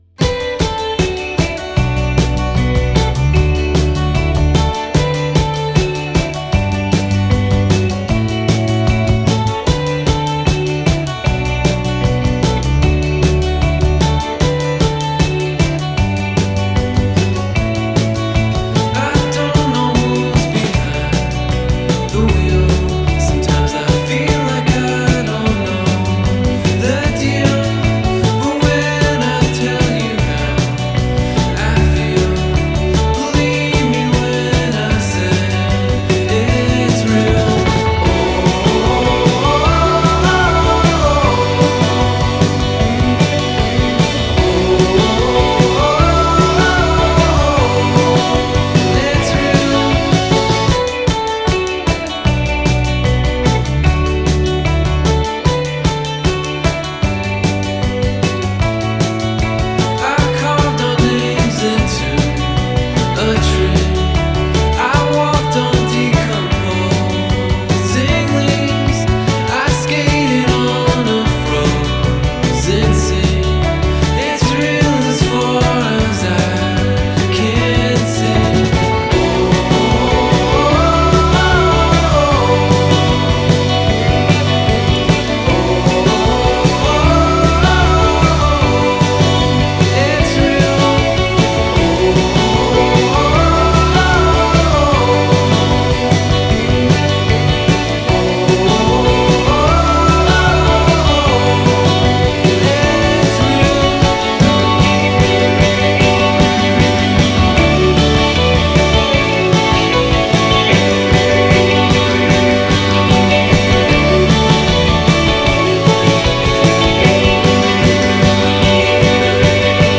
suona bella nitida